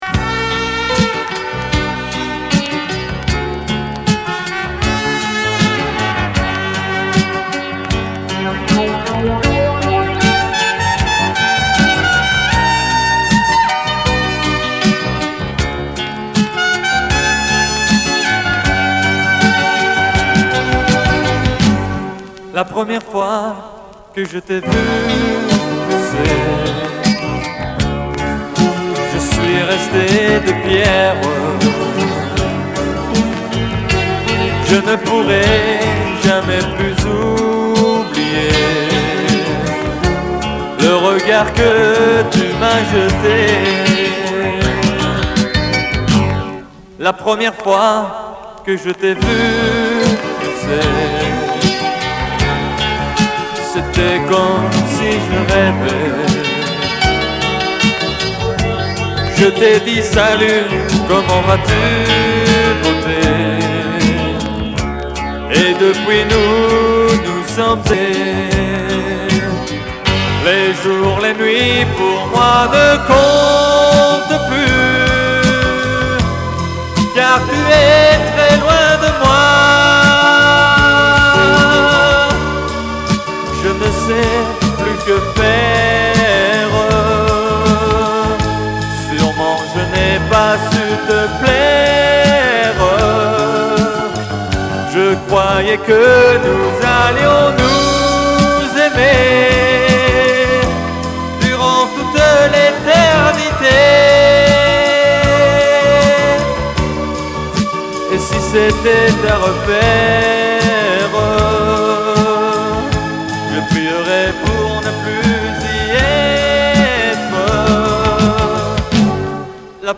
Предполагают, что это RADIO K7 (Франция), но там ещё и скрип винила.